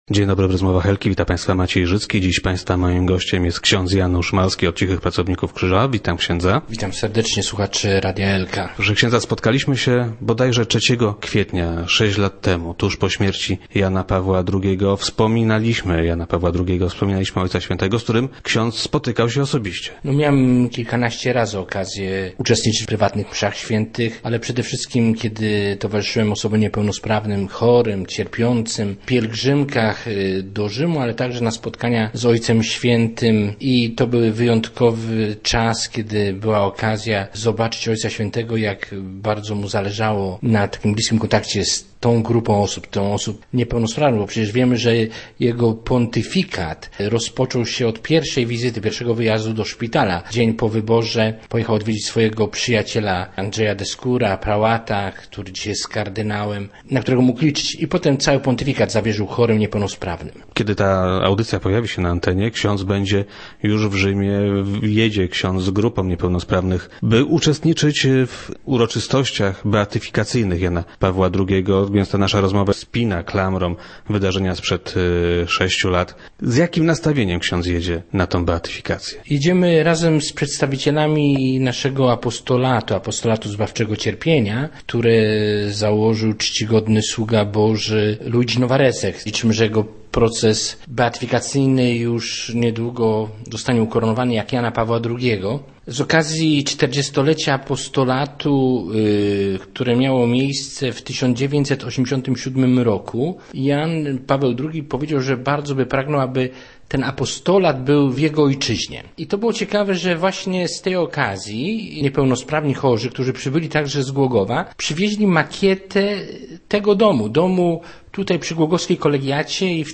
- Kilkanaście razy miałem okazję uczestniczyć w prywatnych mszach świętych z Janem Pawłem II. Przede wszystkim jednak spotykałem się z nim wówczas, kiedy towarzyszyłem osobom niepełnosprawnym i chorym w spotkaniach z Ojcem Świętym - mówił na radiowej antenie duchowny.